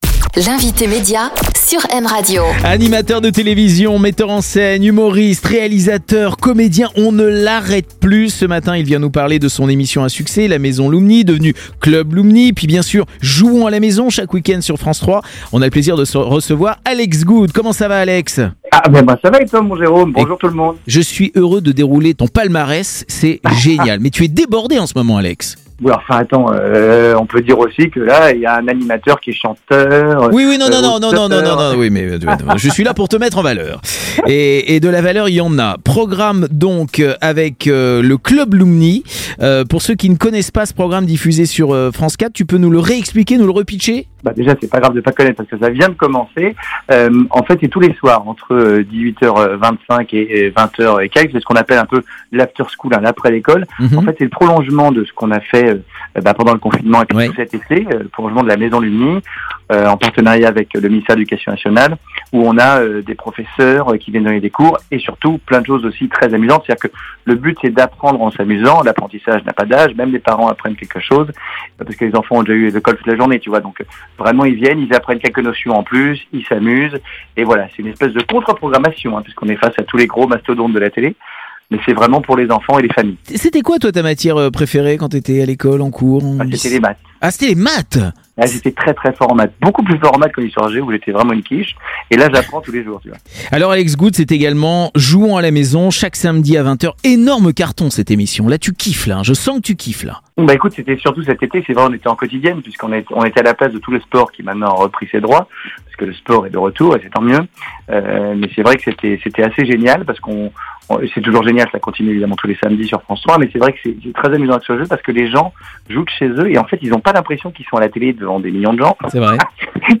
Alex Goude était au micro de M Radio, avec Jerôme Anthony pour parler de son émission "La Maison Lumni" sur France 4